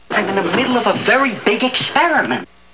sound_big_experiment.wav